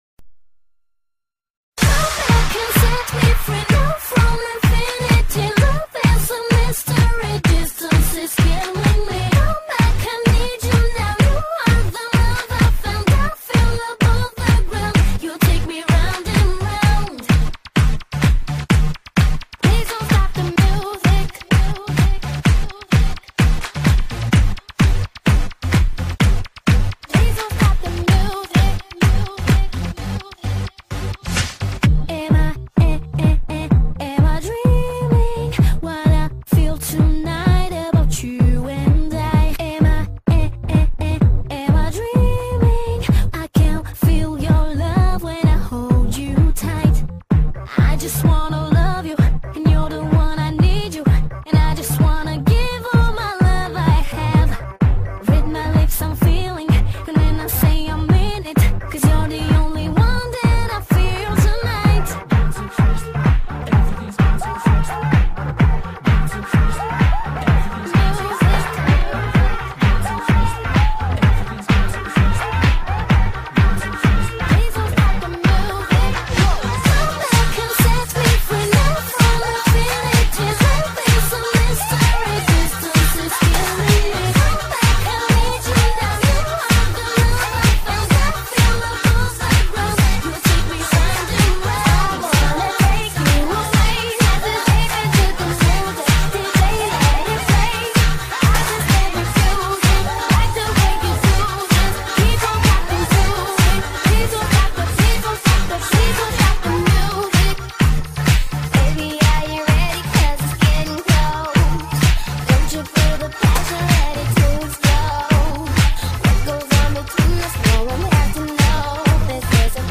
House | [